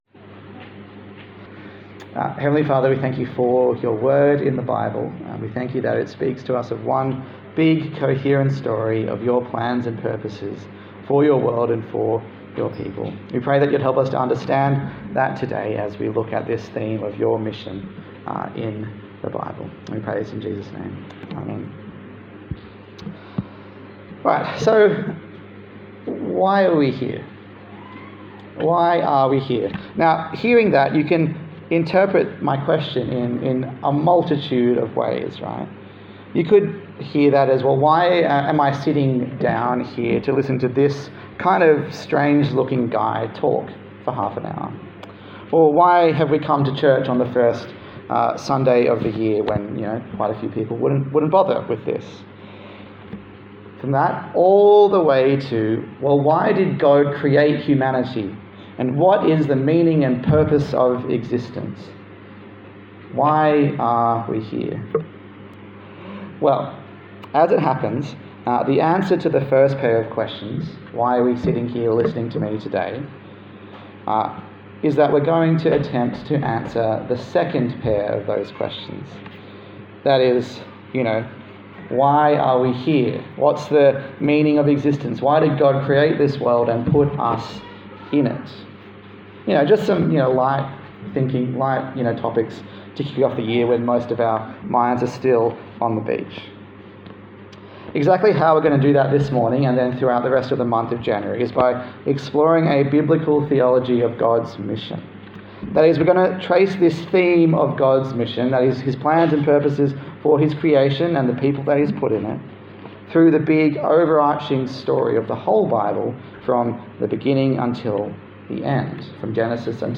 God's Mission Passage: Genesis 1:26-31 Service Type: Morning Service